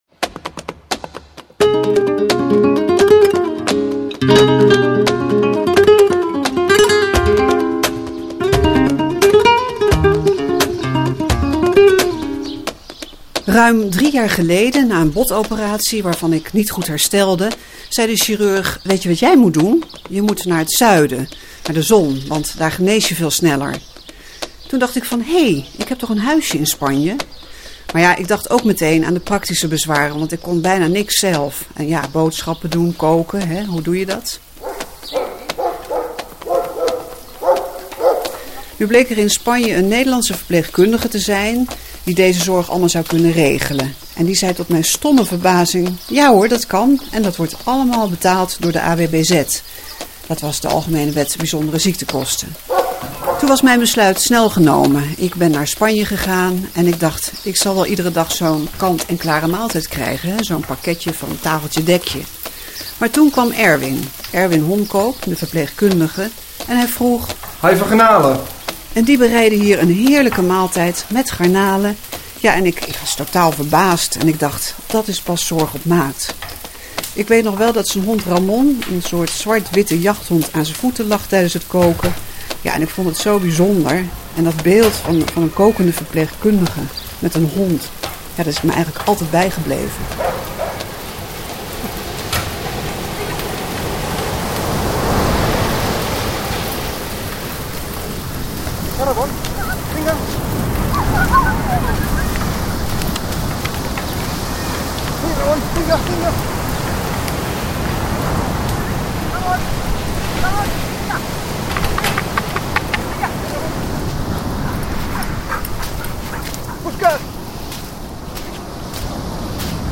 geinterviewed